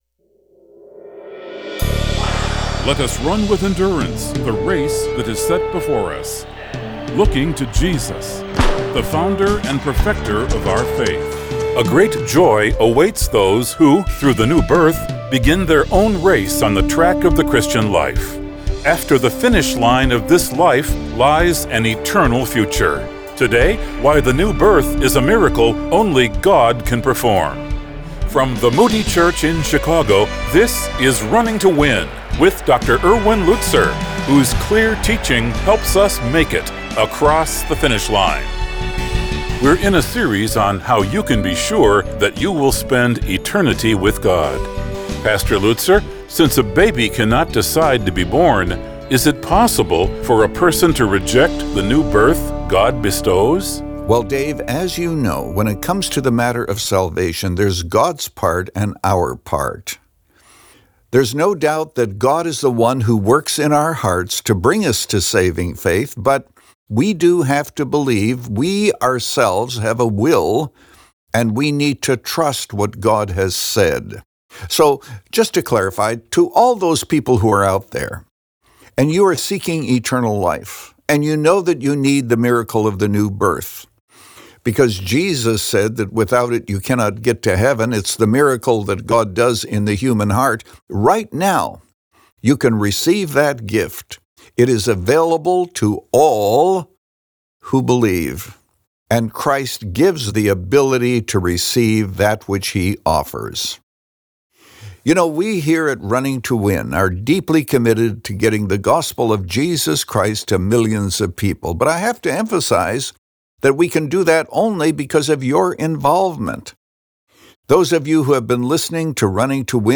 The Miracle We Need – Part 2 of 2 | Radio Programs | Running to Win - 25 Minutes | Moody Church Media